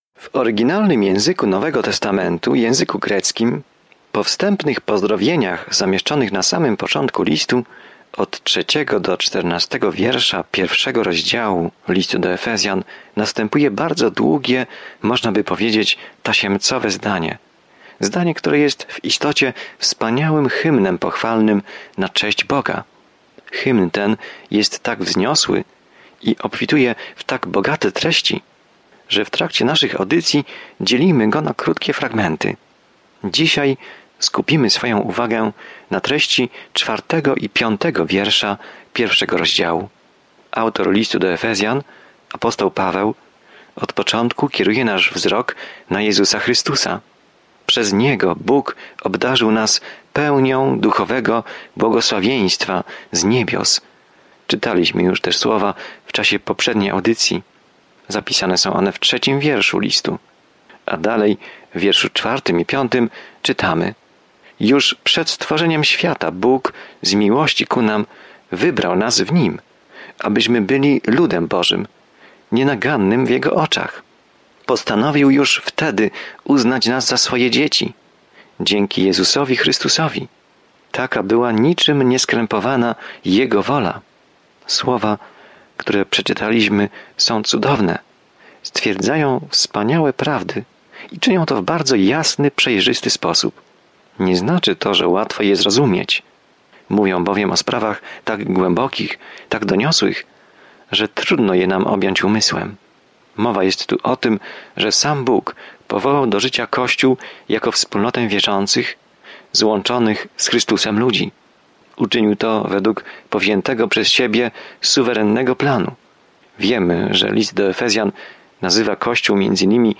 Pismo Święte Efezjan 1:4-5 Dzień 2 Rozpocznij ten plan Dzień 4 O tym planie List do Efezjan wyjaśnia, jak żyć w Bożej łasce, pokoju i miłości, ukazując piękne wyżyny tego, czego Bóg pragnie dla swoich dzieci. Codziennie podróżuj przez Efezjan, słuchając studium audio i czytając wybrane wersety słowa Bożego.